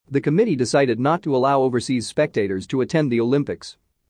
このままの速度でお聞きください。
【ノーマル・スピード】